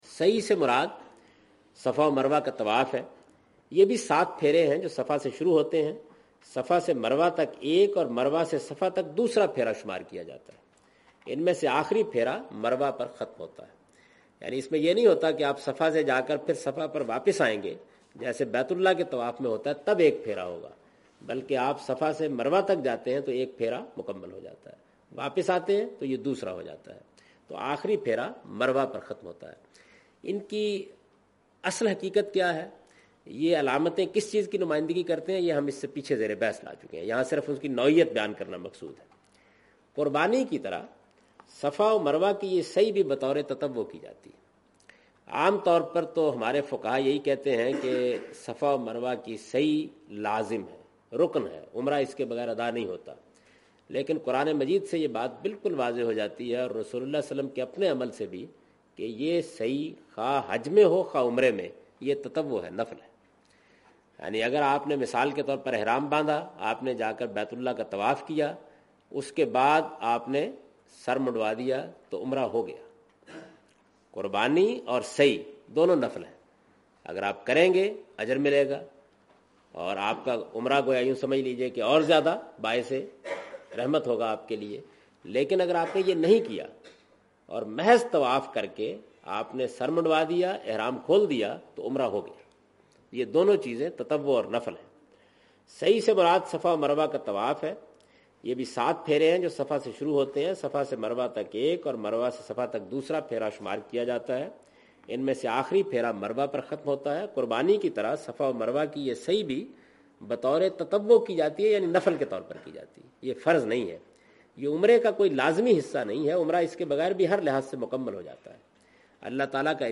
In this video of Hajj and Umrah, Javed Ahmed Ghamdi is talking about "Saee of Safa and Marwa".
حج و عمرہ کی اس ویڈیو میں جناب جاوید احمد صاحب غامدی "صفا اور مرویٰ کی سعی" سے متعلق گفتگو کر رہے ہیں۔